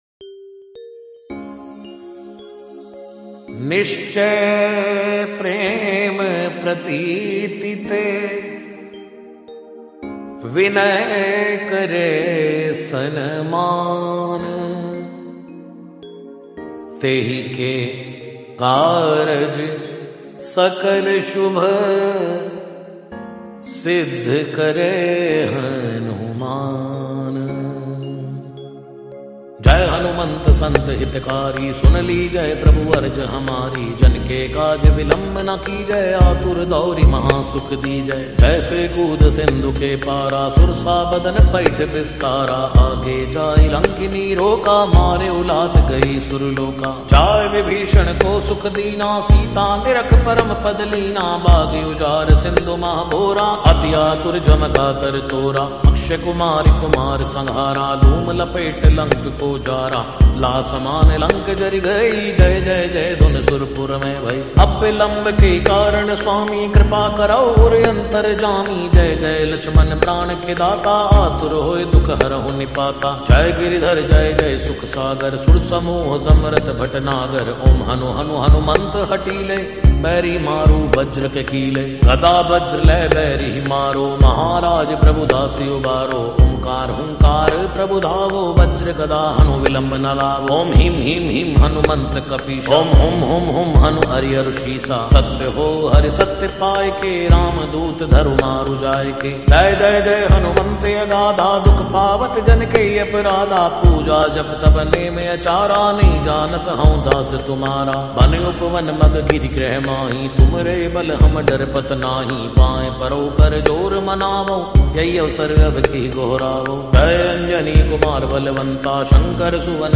Lofi